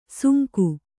♪ sunku